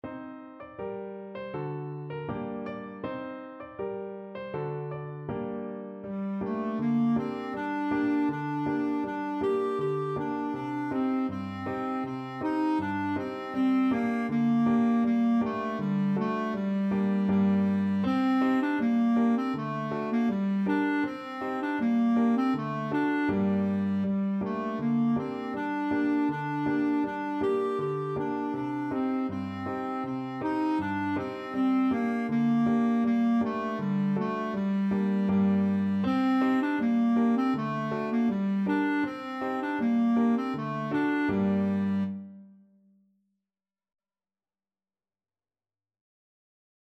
Clarinet
Traditional Music of unknown author.
G minor (Sounding Pitch) A minor (Clarinet in Bb) (View more G minor Music for Clarinet )
Fast =c.160
4/4 (View more 4/4 Music)